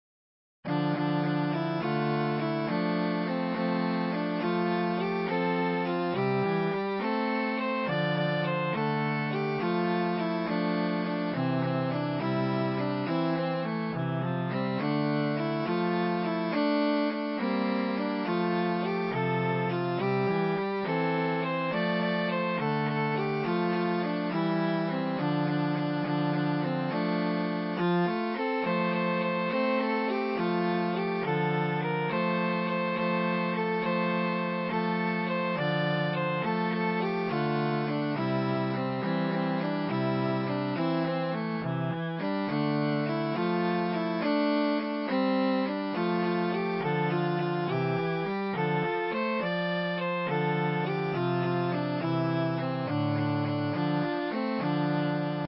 Play MP3 instrumental)
The tune should perhaps be printed in 6/8 rather than 3/4 to emphasize its jig-ish nature.